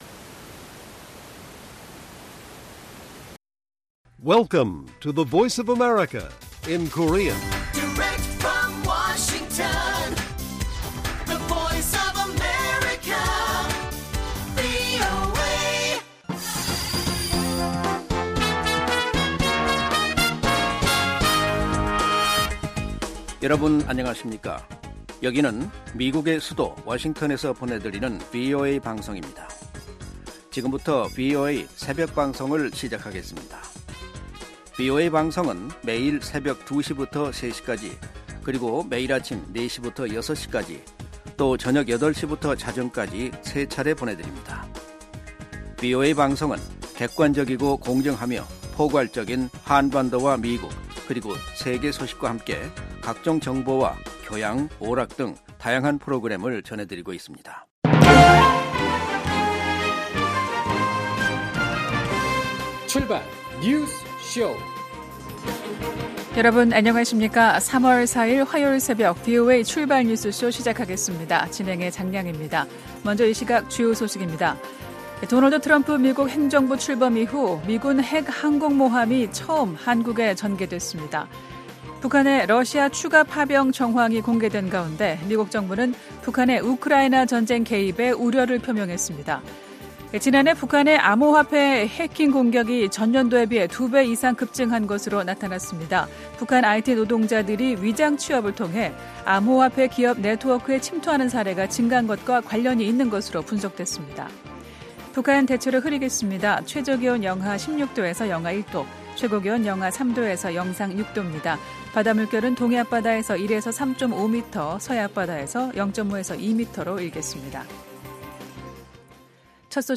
VOA 한국어 '출발 뉴스 쇼' 입니다. 도널드 트럼프 미국 행정부 출범 이후 미군 핵 항공모함이 처음 한국에 전개됐습니다. 북한의 러시아 추가 파병 정황이 공개된 가운데 미국 정부는 북한의 ‘우크라이나 전쟁’ 개입에 우려를 표명했습니다.